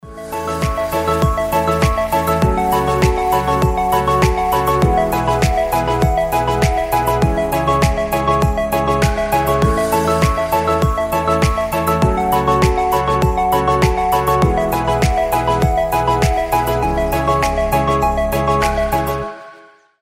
Красивая свободная музыка